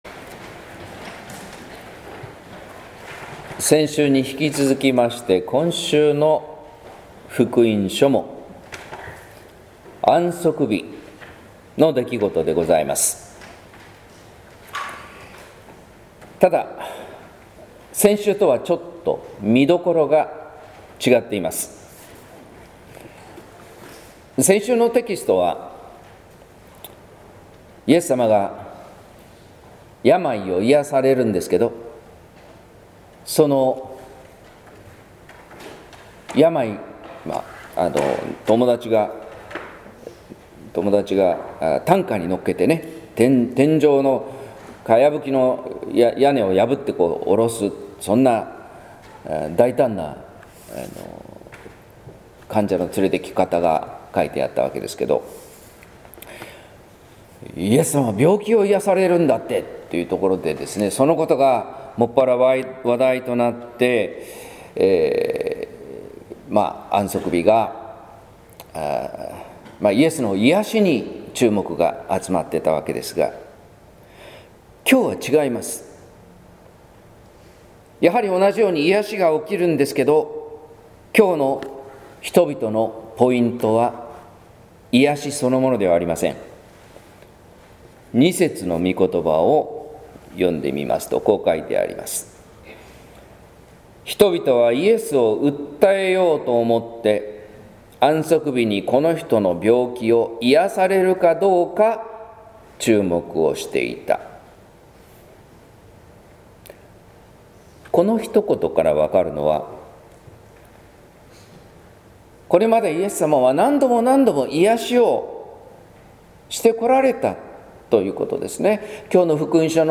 説教「こだわりを捨てる」（音声版） | 日本福音ルーテル市ヶ谷教会